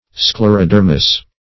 Search Result for " sclerodermous" : The Collaborative International Dictionary of English v.0.48: Sclerodermic \Scler`o*der"mic\, Sclerodermous \Scler`o*der"mous\, (Zool.) (a) Having the integument, or skin, hard, or covered with hard plates.